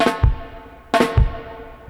62-FX-FILL.wav